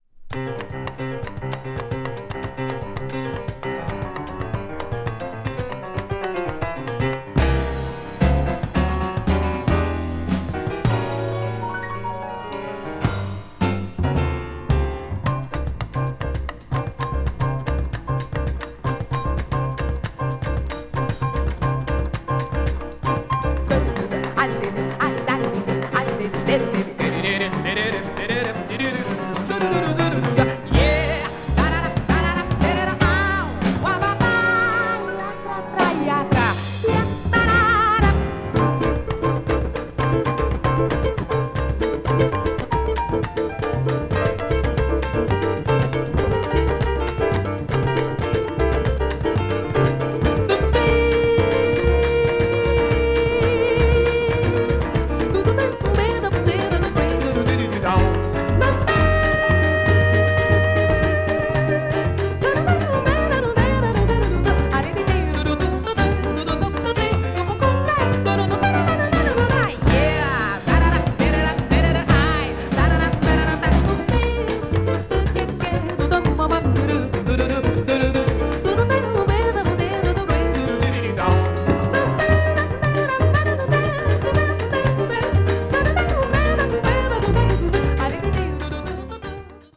media : VG+/EX(わずかにチリノイズが入る箇所あり,A:再生に影響ない軽い歪みあり)